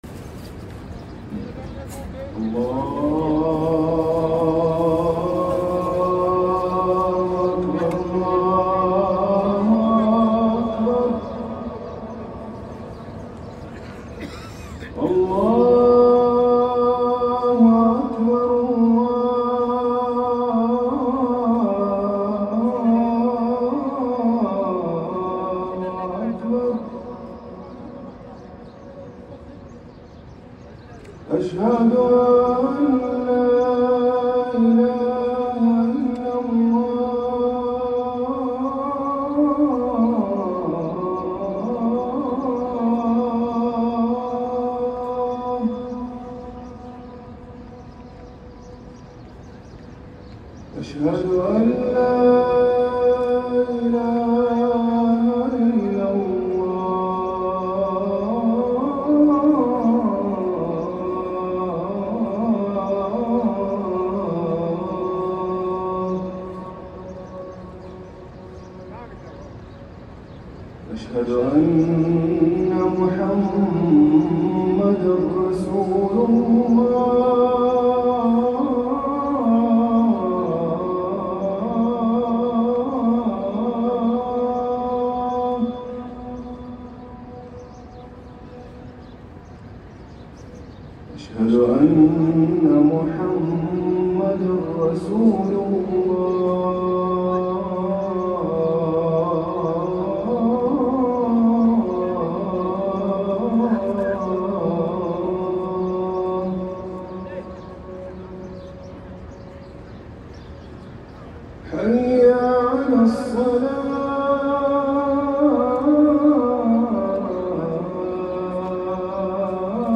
الأذان الأول لصلاة الفجر > ركن الأذان 🕋